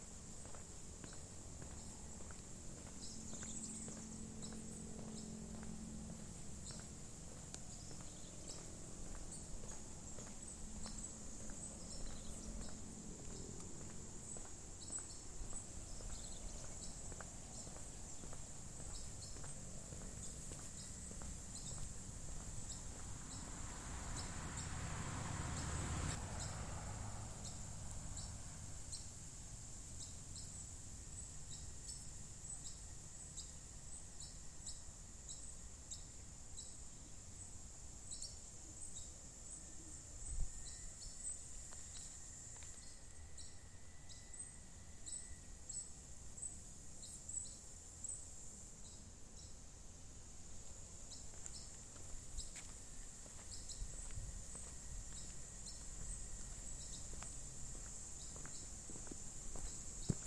Borboletinha-do-mato (Phylloscartes ventralis)
Nome em Inglês: Mottle-cheeked Tyrannulet
Localidade ou área protegida: Cerro San Javier
Condição: Selvagem
Certeza: Gravado Vocal
MOSQUETA-CARASUCIA-mp3.mp3